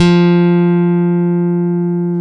Index of /90_sSampleCDs/Roland LCDP02 Guitar and Bass/BS _Rock Bass/BS _Chapmn Stick